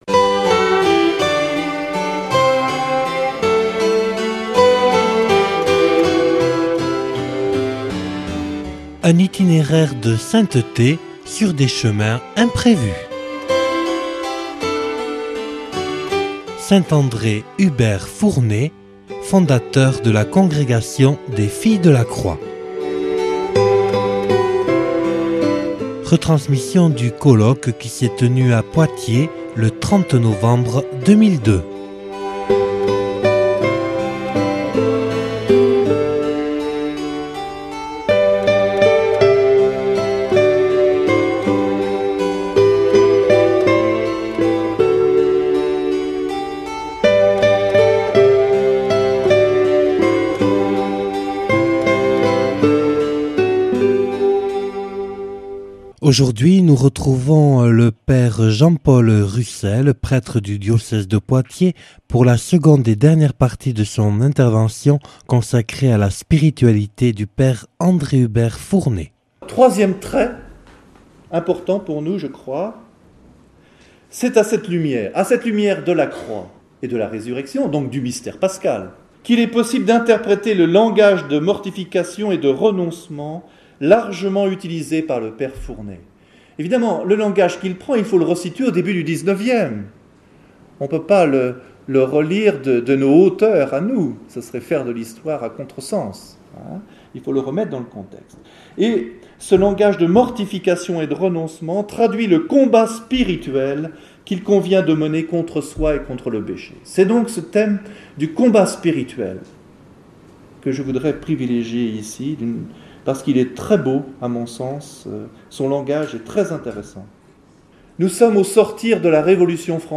(Colloque enregistré le 30/11/2002 à Poitiers).